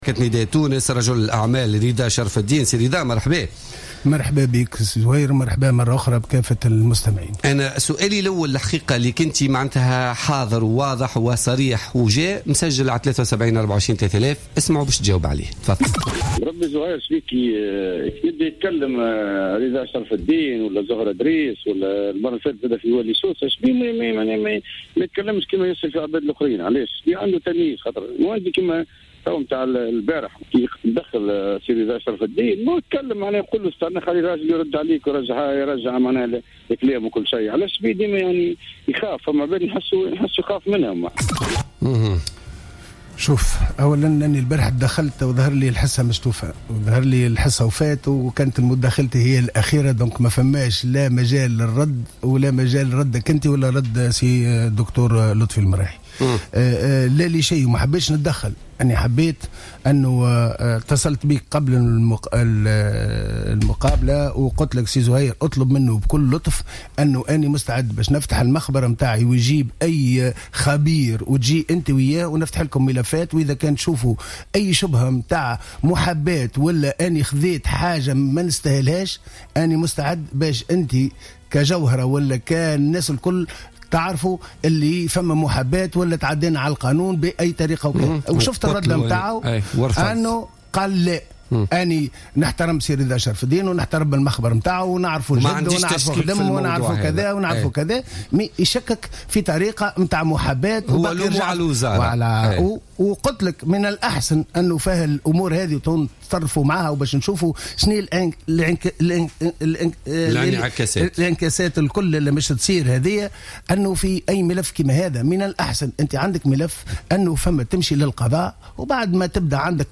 أكد النائب بمجلس نواب الشعب عن حزب نداء تونس رضا شرف الدين ضيف بوليتيكا اليوم الخميس 11فيفري 2016 أنه سيتوجه للقضاء ضد الأمين العام لحزب الاتحاد الشعبي الجمهوري لطفي المرايحي بتهمة التشويه.